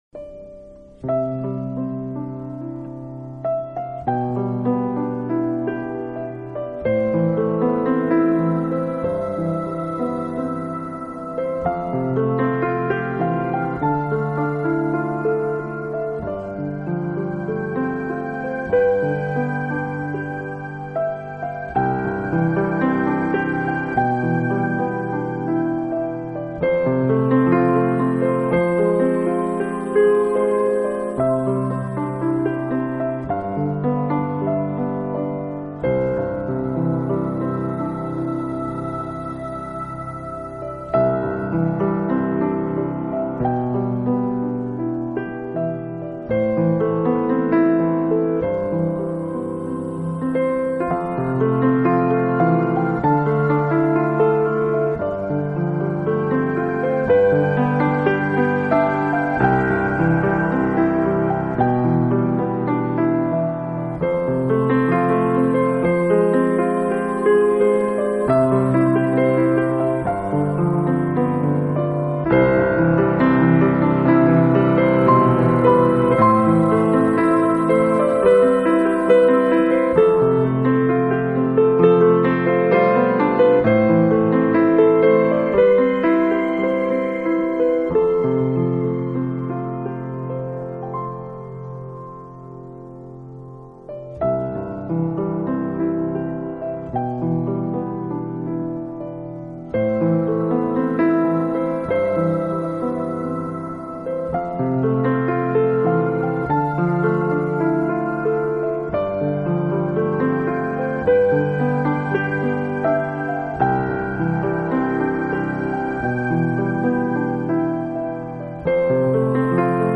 很喜欢斯坦威牌钢琴 的音色，特别纯净，不留一丝浮躁。